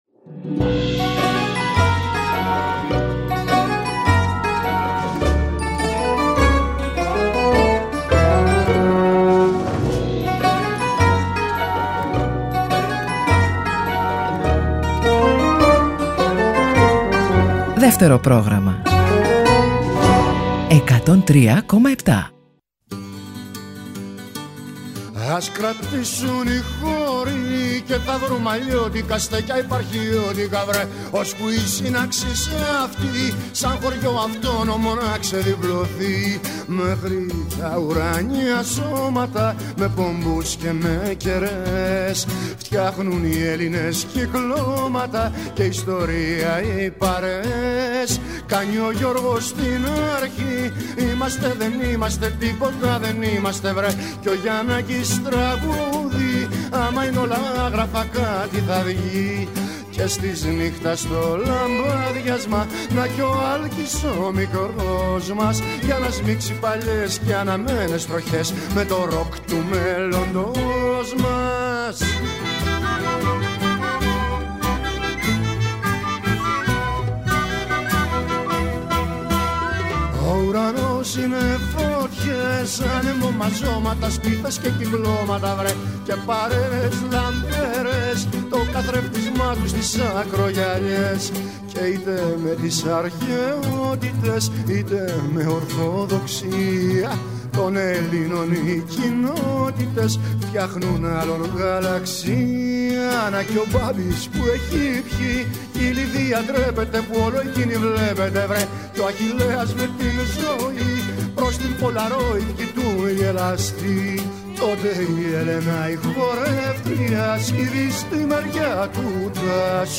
με τραγούδια και μικρές ιστορίες